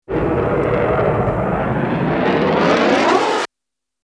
На этой странице собраны реалистичные звуки пуль — от одиночных выстрелов до очередей.
Пули - Альтернативный вариант 2